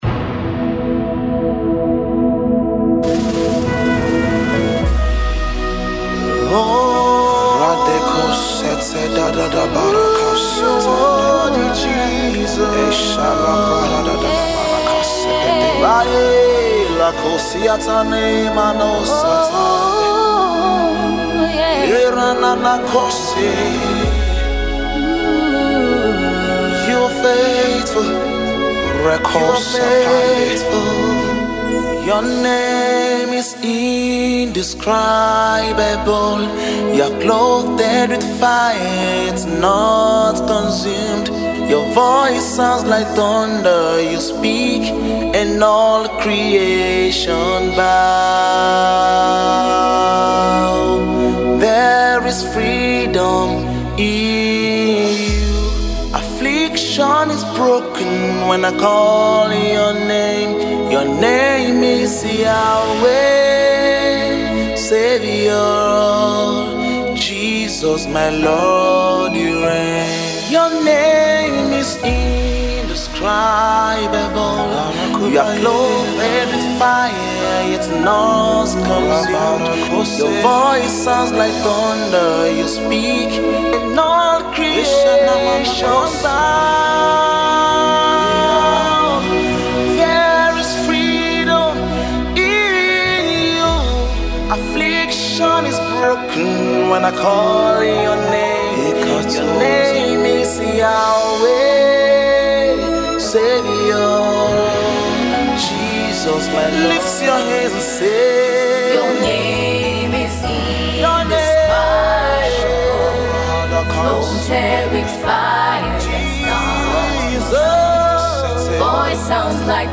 Gospel music
worship song